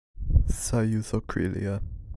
How to Pronounce "Union of Krylia"